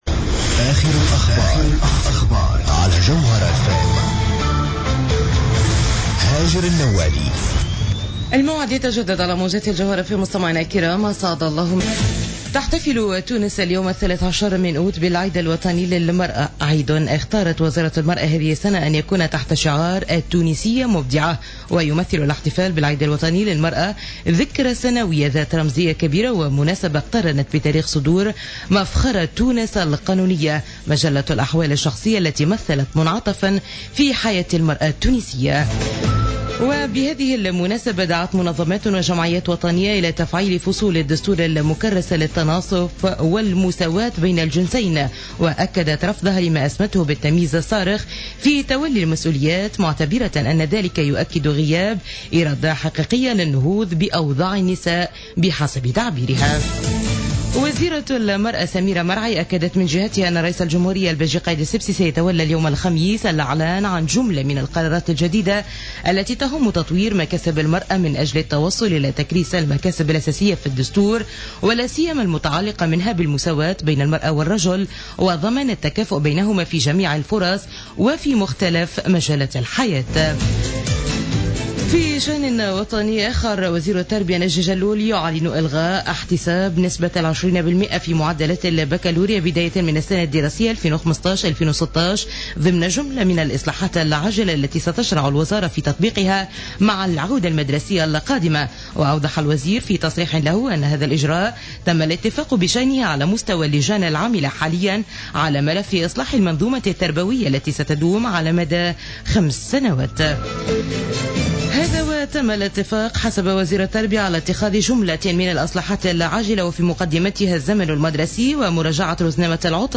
نشرة أخبار منتصف الليل ليوم الخميس 13 أوت 2015